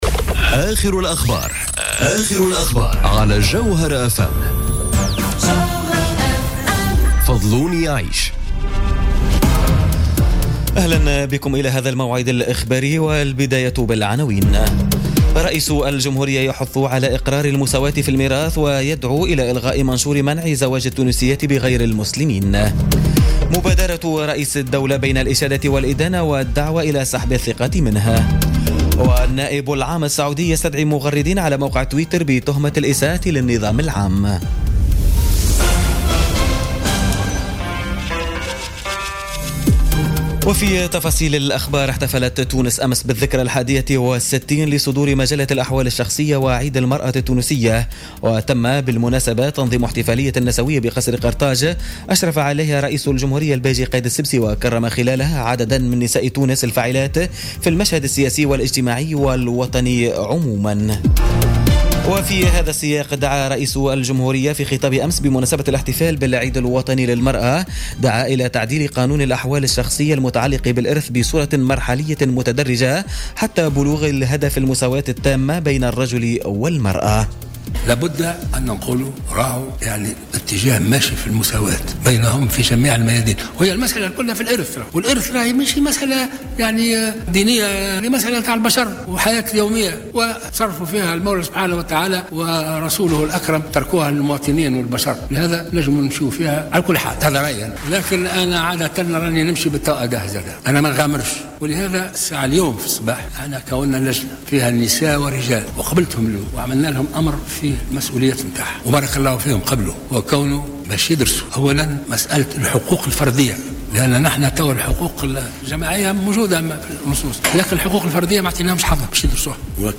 نشرة أخبار منتصف الليل ليوم الاثنين 14 أوت 2017